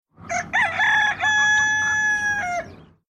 ACTIVITAT 8. QUIN ANIMAL FA AQUEST SOROLL?